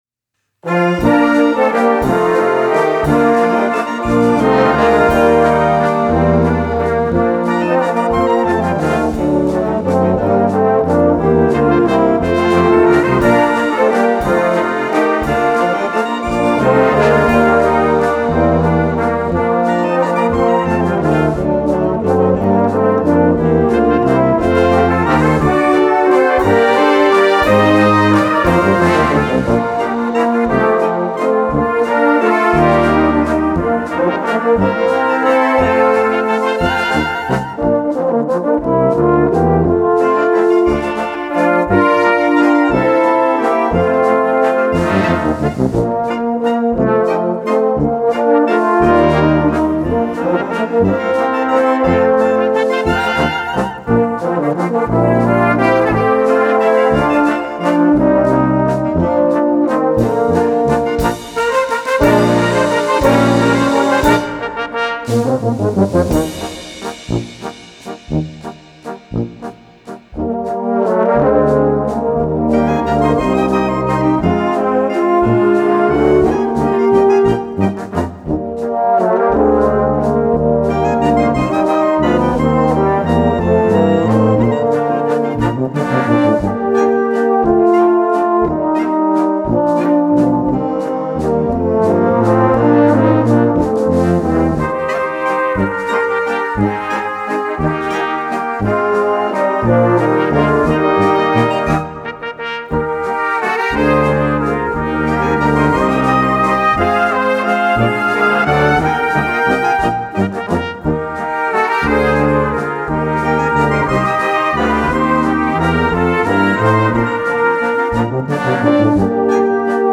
Walzer für Blasmusik